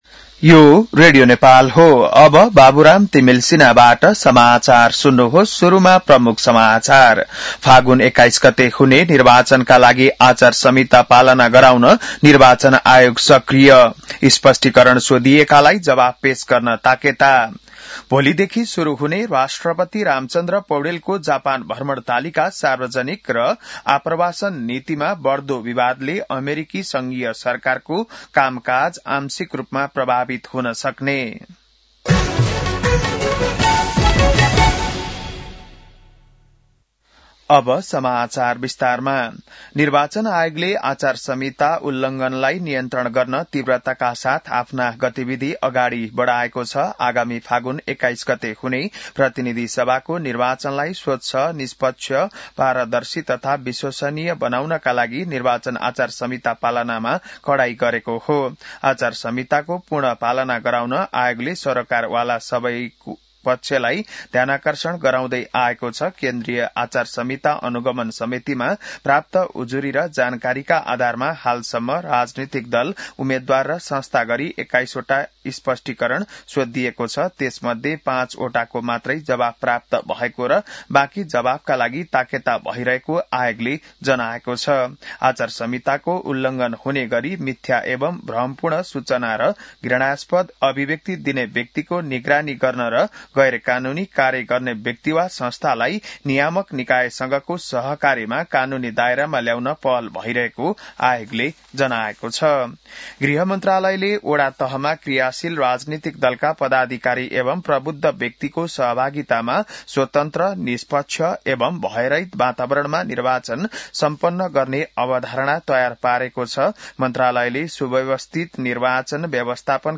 बिहान ९ बजेको नेपाली समाचार : १७ माघ , २०८२